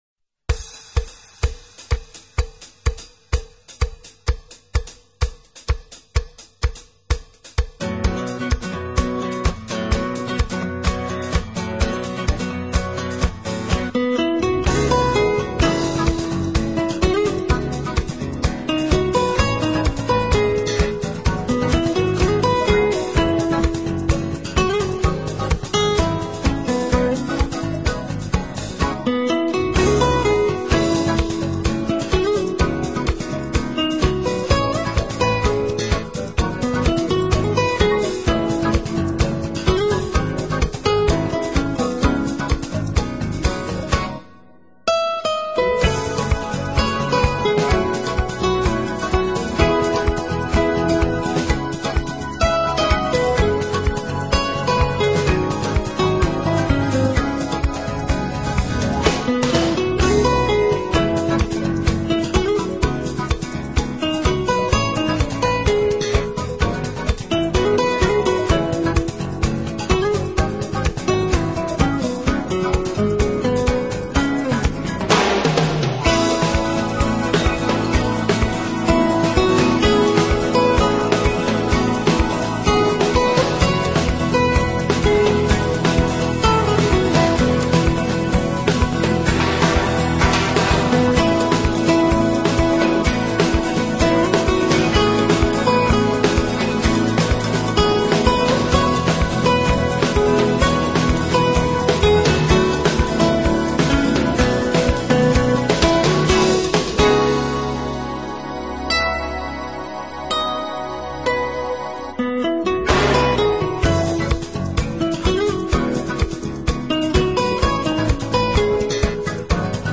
音乐类型：Jazz 爵士
音乐风格：NEWAGE，Smooth Jazz，Contemporary，Instrumental